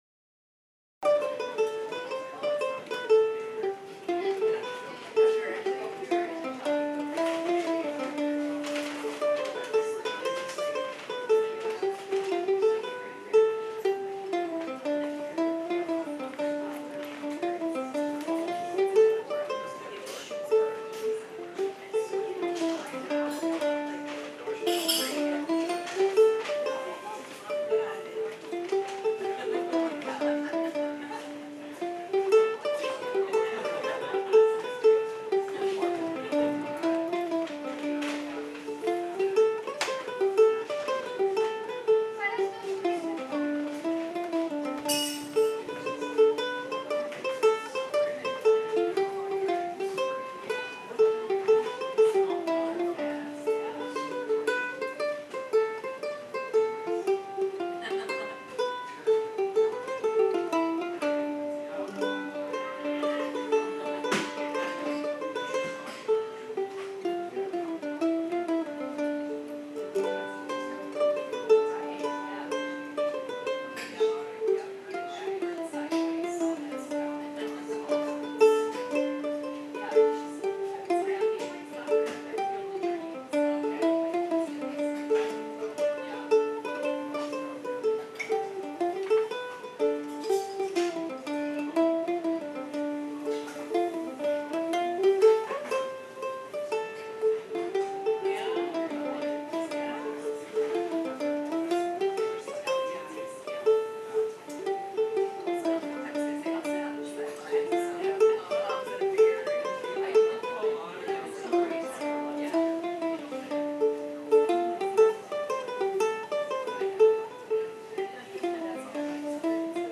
mp3, solo version) (pdf)
Another live recording from Java John's last week. I played this tune as the final number of the evening.
The recording was done with my now vintage Zoom H4 recorder using the built-in mics, direct to mp3 at 320kbps and lightly edited using an even more vintage version Sound Forge Studio.